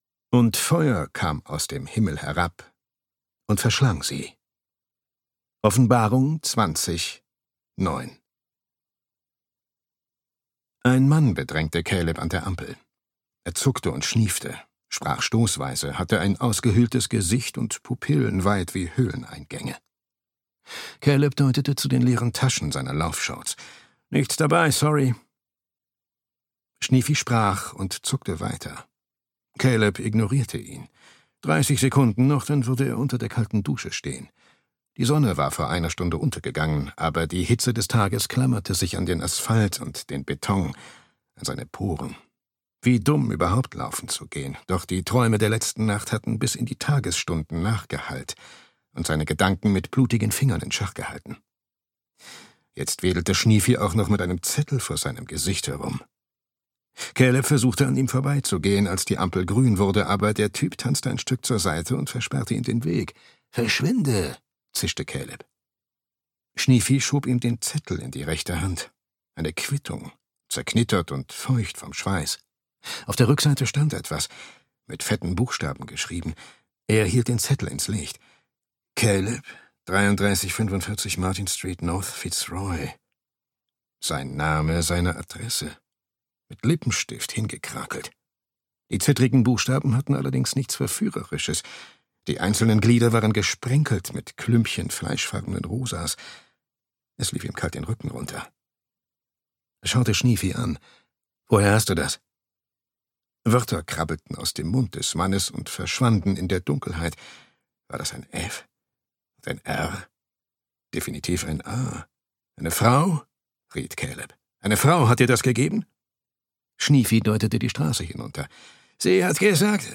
No Words – Die Sprache der Opfer (Caleb Zelic 2) - Emma Viskic - Hörbuch